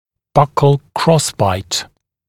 [ˈbʌkl ‘krɔsbaɪt][ˈбакл ‘кросбайт]боковой перекрестный прикус